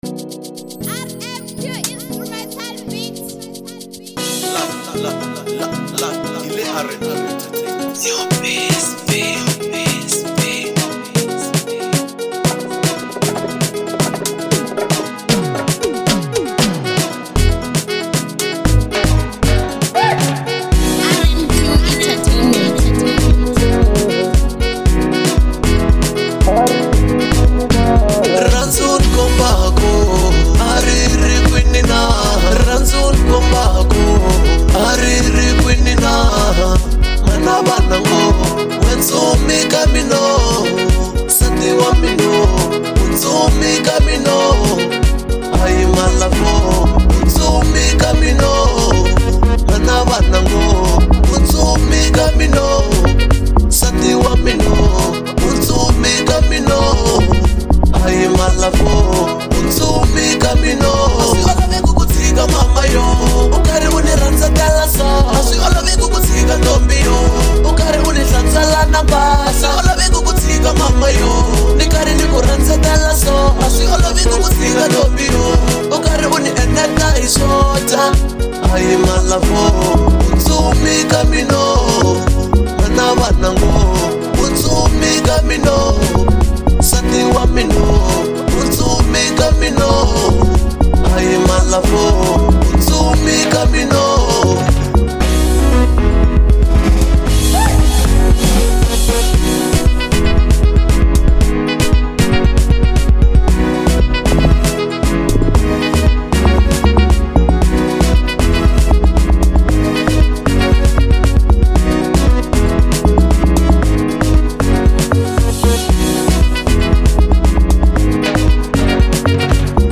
04:49 Genre : Amapiano Size